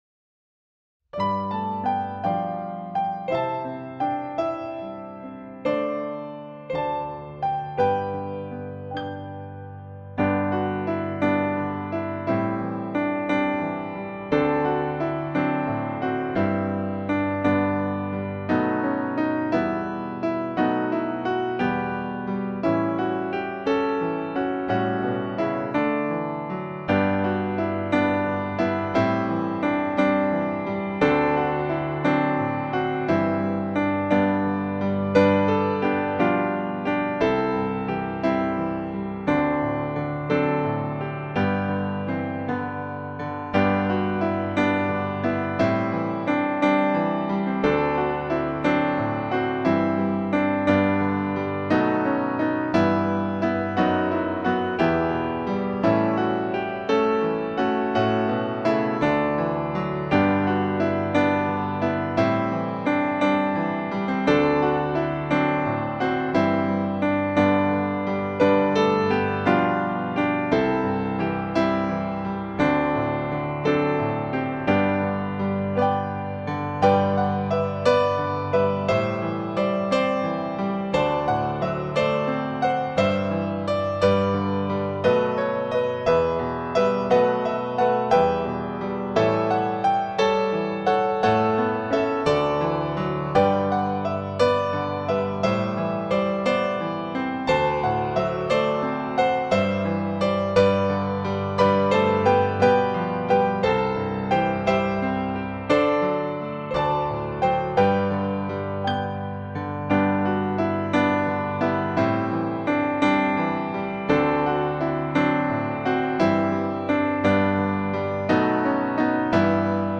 Open My Eyes That I May See - piano instrumental
Open-My-Eyes-That-I-May-See-piano.mp3